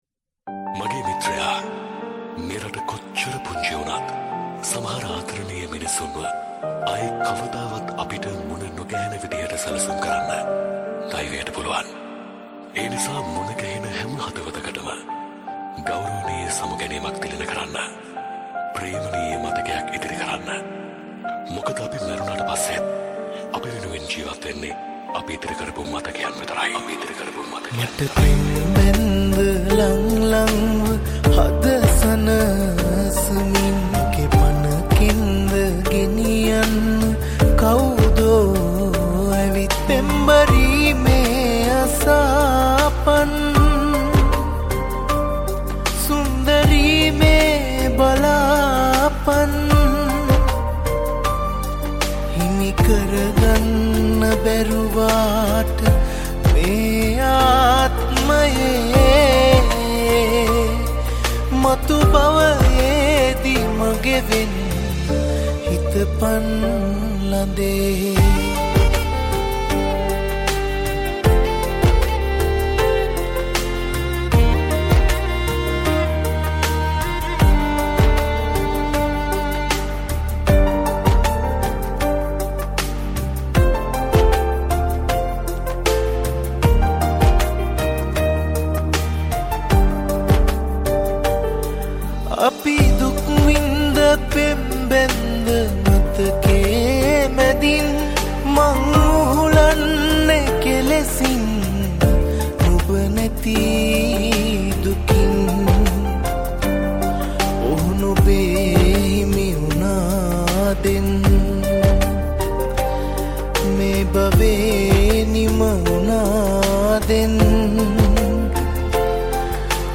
Other vocals